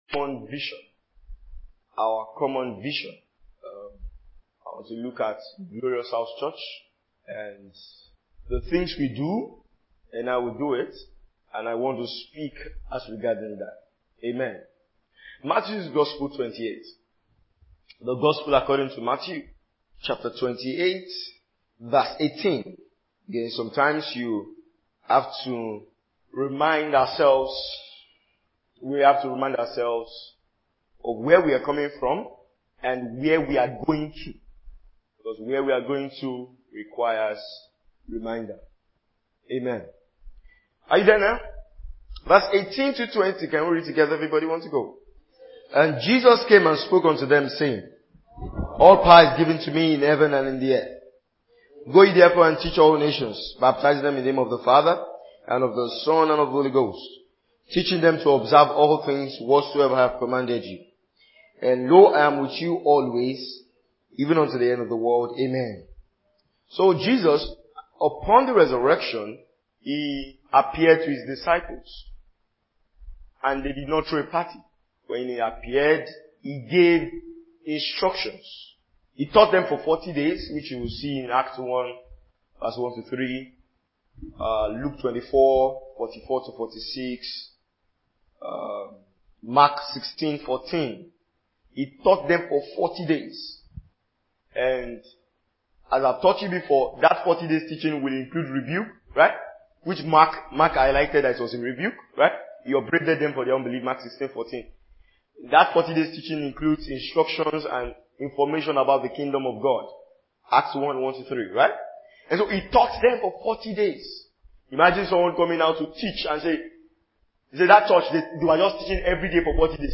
2022 Glorious House Church Teachings.